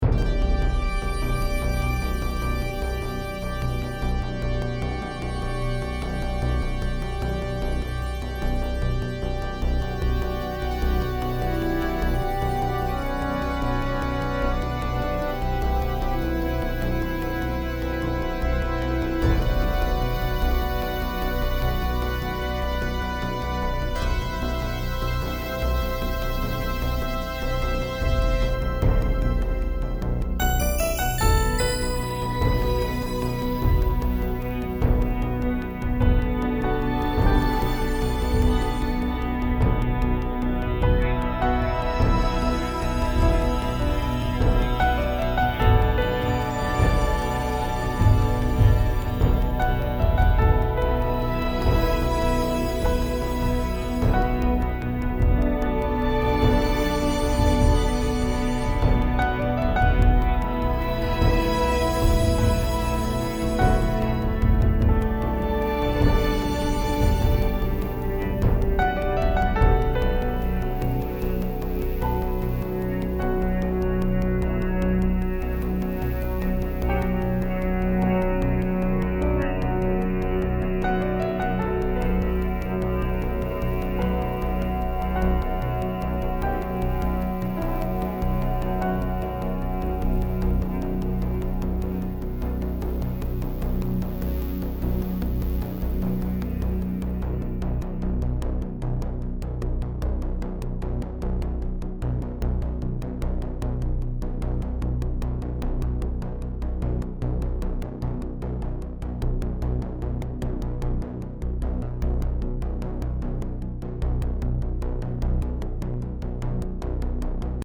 Improvisationen music for film
Stimmung und Atmosphäre erschaffen mit Sound Design und nur wenigen Tönen, nicht mehr als drei Töne, war die eigene Vorgabe.
Auflösung der typischen Lied- oder Songstruktur, hin zum Fade In/ Fade Out Motiv……
Fender Telecaster Custom 1974
Fender Akustik Gitarre
und das alte Nordiska Piano futura….